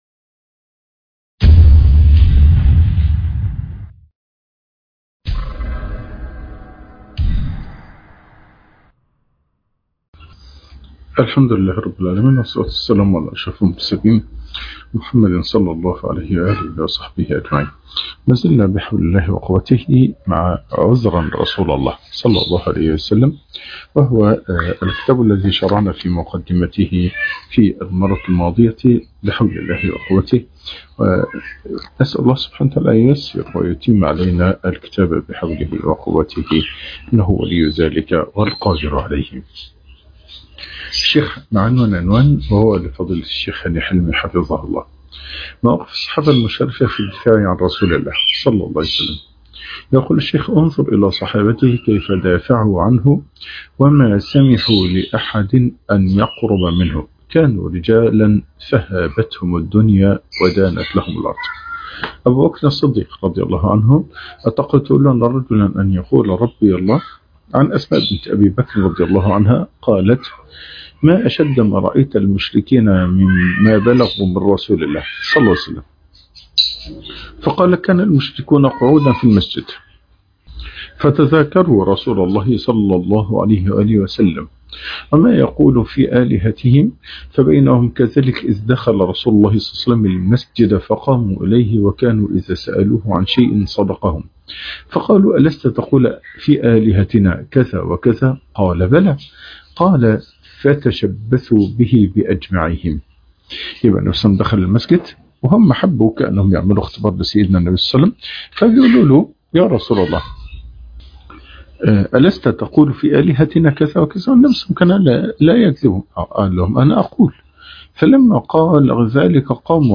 شرح كتاب عذرآ رسول الله - الدرس الثاني